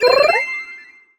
collect_item_01.wav